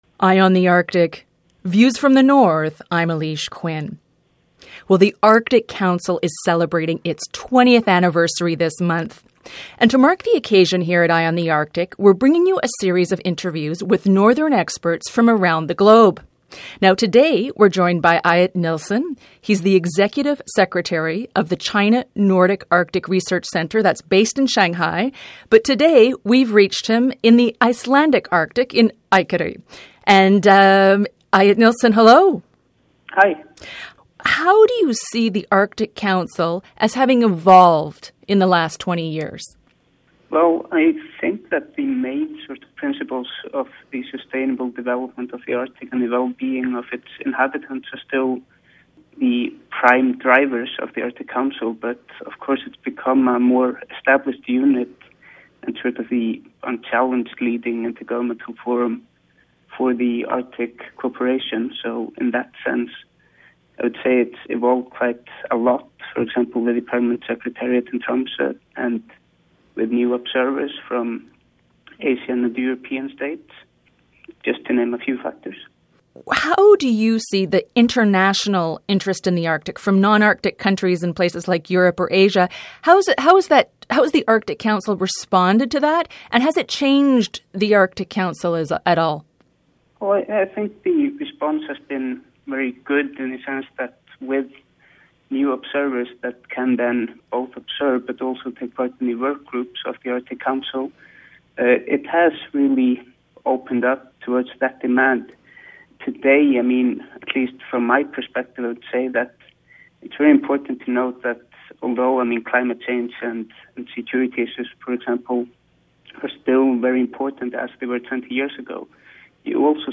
He spoke to Eye on the Arctic from Akureyri, Iceland.